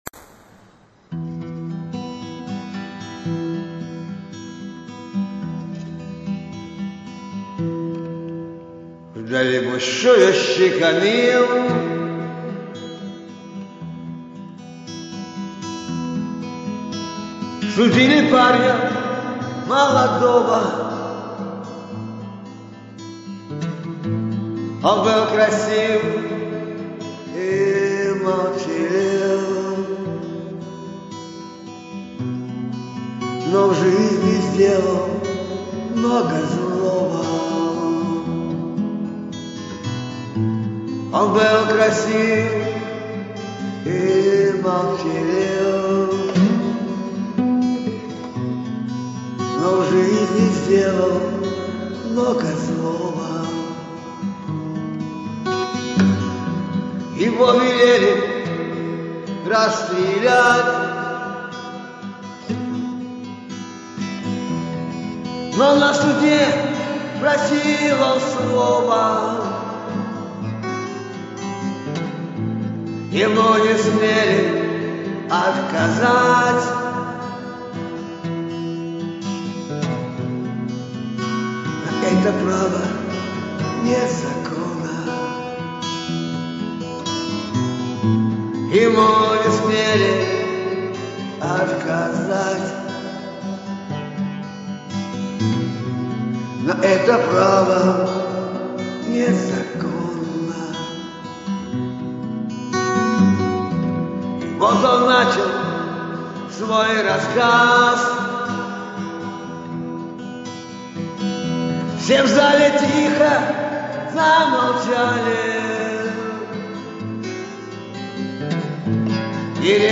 Печальная песенка,со смыслом